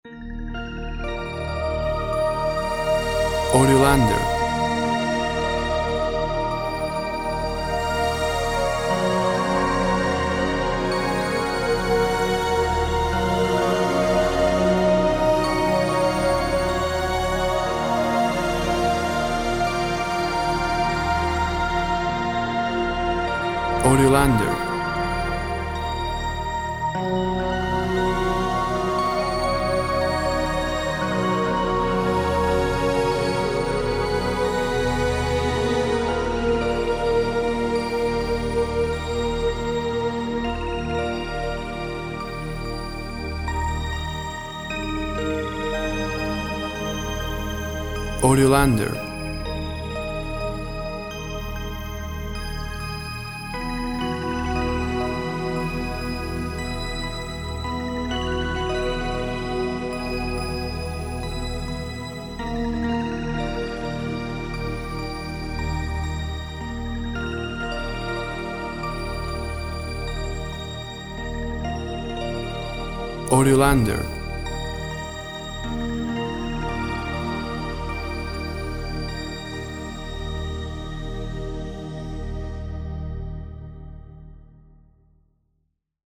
Lush multi-layered dreamy synth sounds.
Tempo (BPM) 54